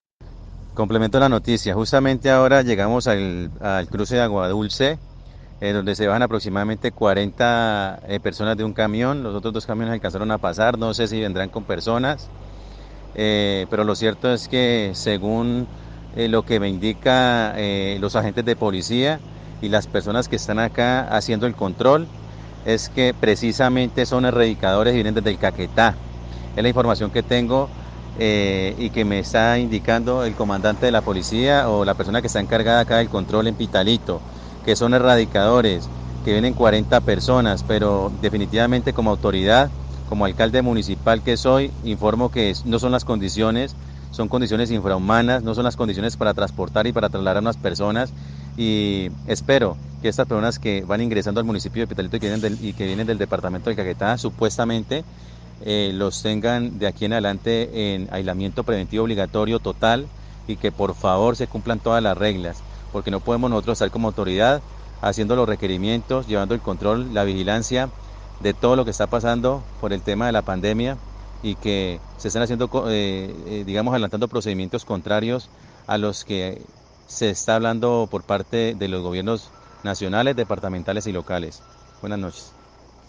2 LLAMADO ACLARANDO Y CONFIRMANDO QUE SON ERRADICADORES DICE ALCALDE. AUDIO
AUDIOS DEL ALCALDE DE TIMANÁ CON LLAMADO DE SOS Y ADVERTENCIAS.